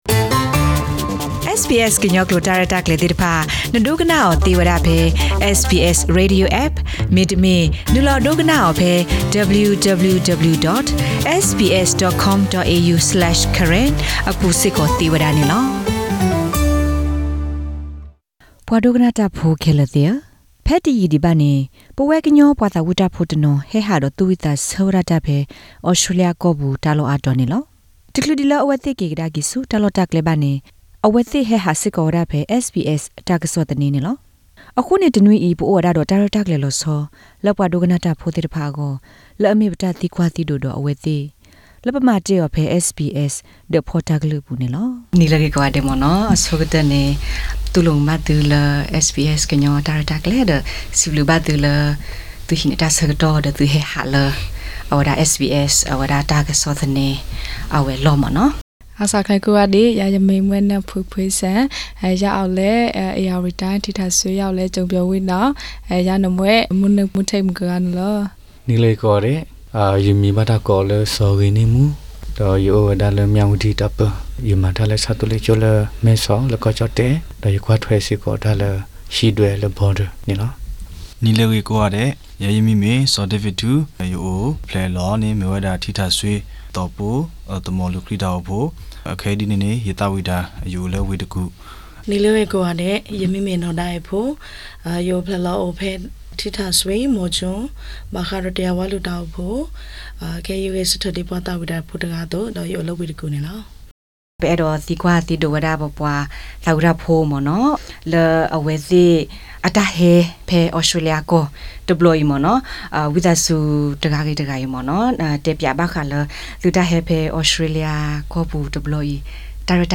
Exclusive Interview with Karen singers from Myanmar